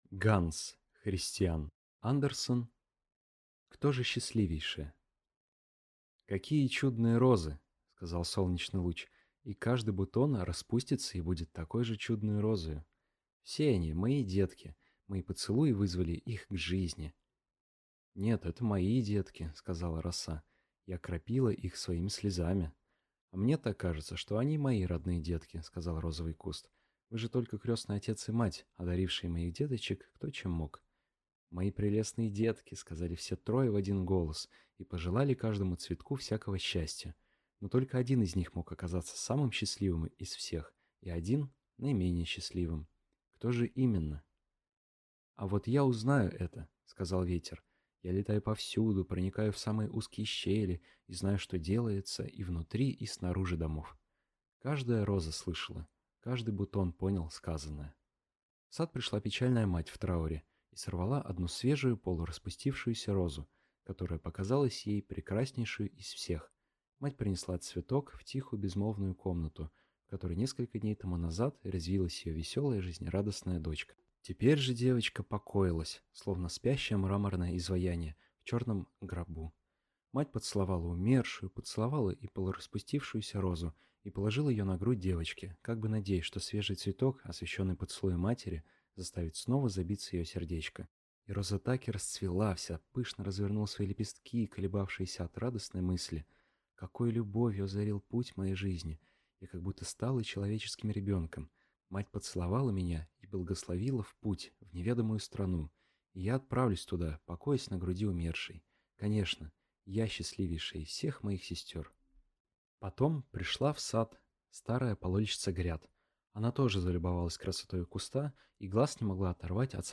Аудиокнига Кто же счастливейшая?